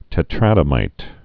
(tĕ-trădə-mīt)